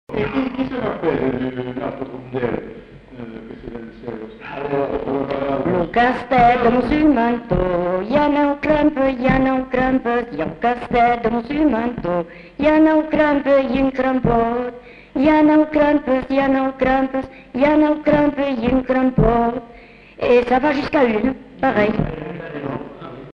Aire culturelle : Bazadais
Lieu : Cazalis
Genre : chant
Effectif : 1
Type de voix : voix de femme
Production du son : chanté
Danse : congo
Classification : chansons de neuf